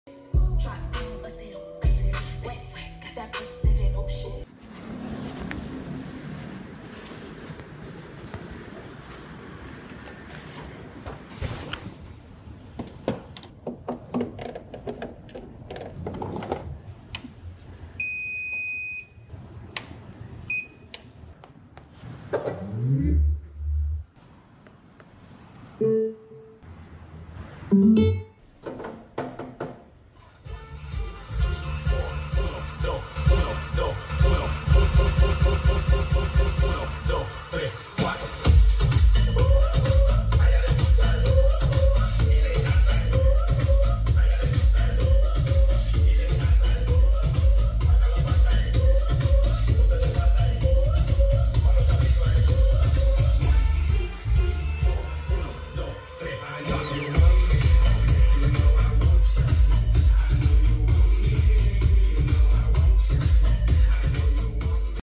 Big Powerful Portable Speaker 🔊 sound effects free download
JBL PartyBox 1000 Sound Testing